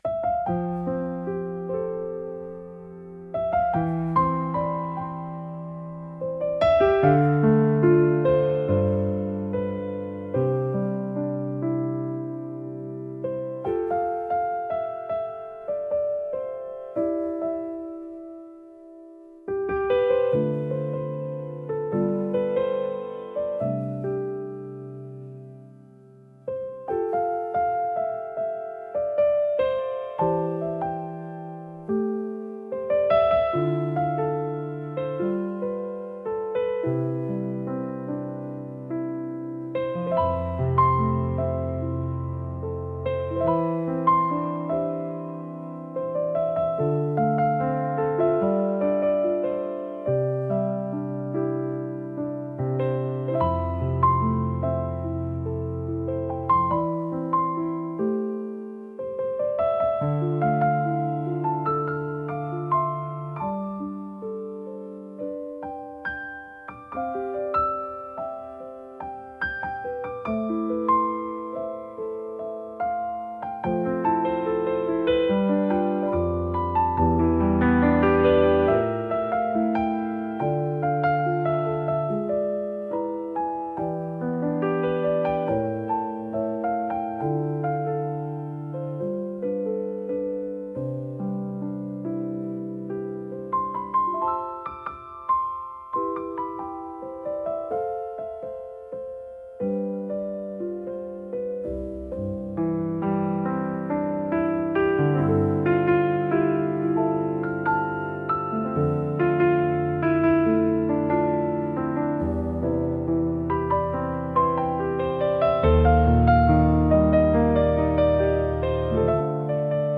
聴く人にやすらぎと温かみを提供し、心を穏やかに整える効果があります。繊細で情感豊かな空気を醸し出すジャンルです。